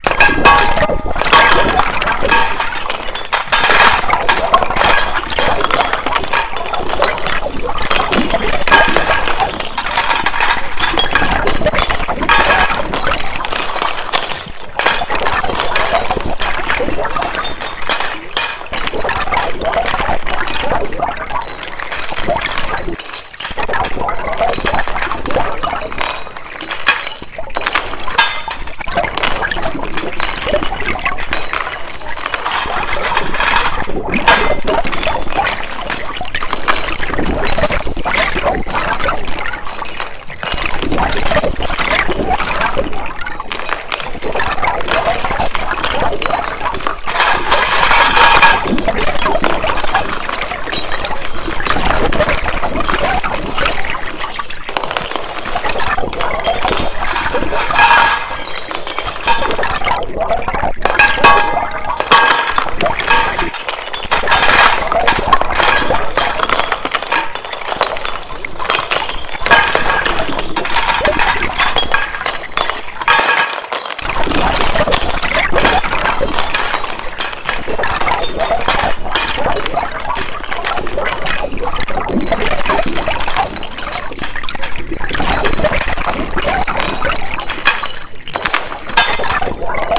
elec. sound installation "flow": water fall, water stream, flow of the street walkers
FROM FIELD RECORDING recomposed from water sounds + broken plates
Interactive sound installation activated by the flow of street walkers passing by / Sound transformed (intensity) by detection of the activity front the window.
Keywords: flow, passages, stream, mixed sound environment, sound installation
sound recording stream river / water fall / underwater sound recording / plates broken sound recording / installation preparation / installation plates / electronic / mp3 player / detector sensor / processing / sound traitment / flow of people detected in the street front  the window / tests / recording video / recording sound in process / in situ
stream_soundinstallation2011.mp3